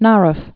(närəf)